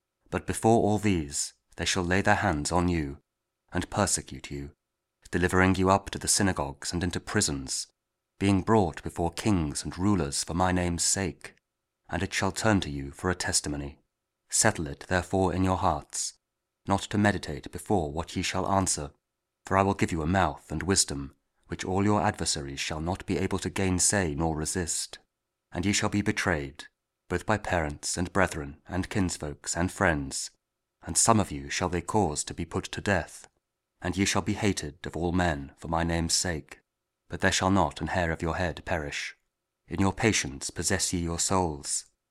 Luke 21: 12-19 – Week 34 Ordinary Time, Wednesday (Audio Bible KJV, Spoken Word)